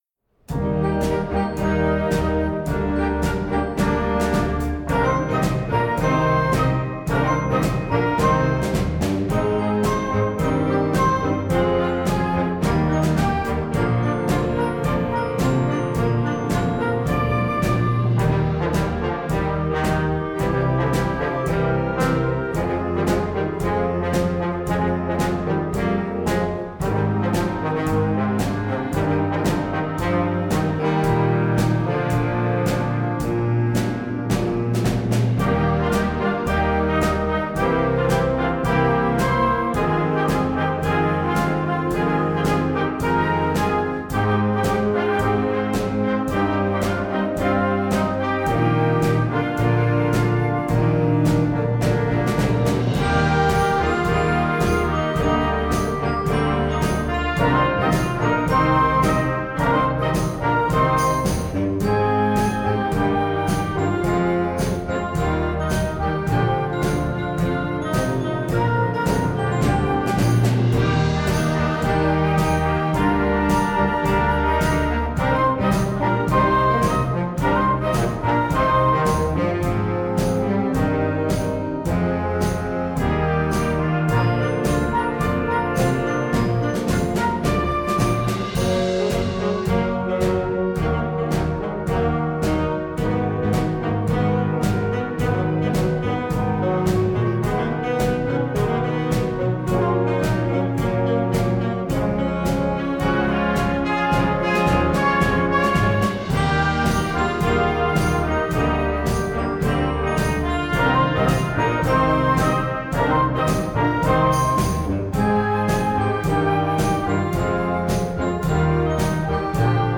Gattung: Moderner Einzeltitel für Jugendblasorchester
Besetzung: Blasorchester